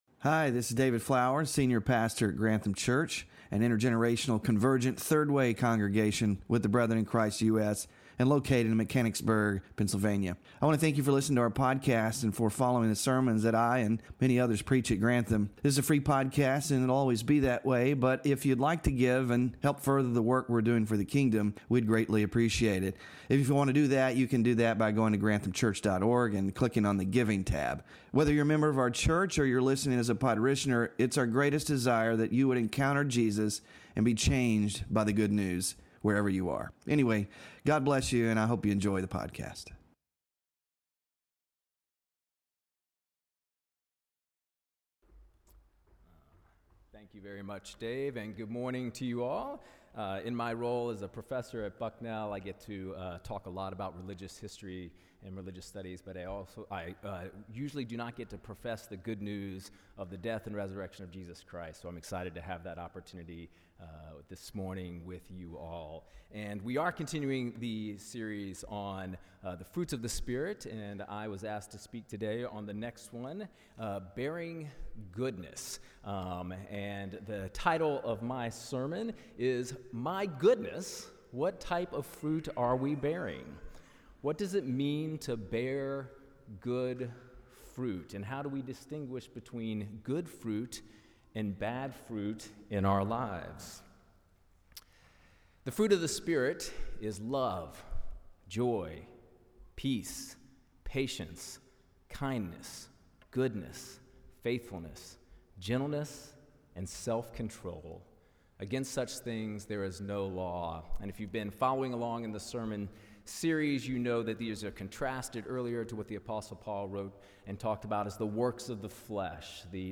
FRUIT OF THE SPIRIT WK6-GOODNESS SERMON SLIDES